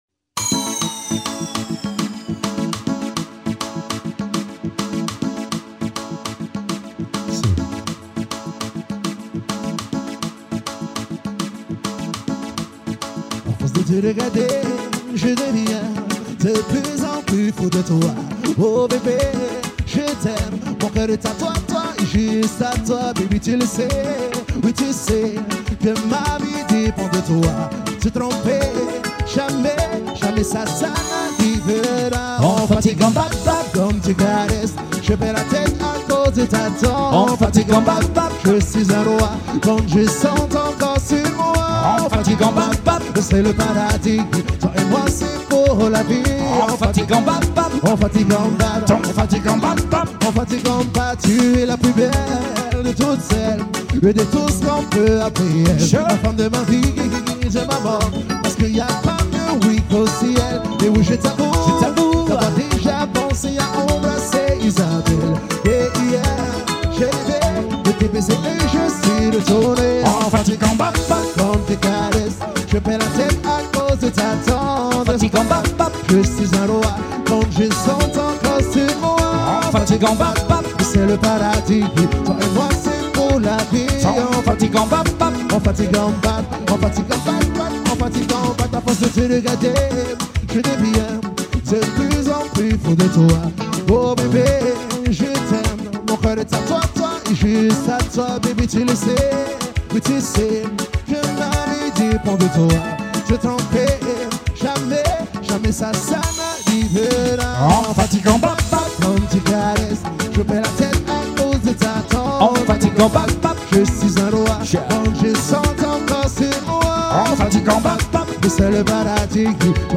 KIZOMBA | MUSIC LOCAL TAHITI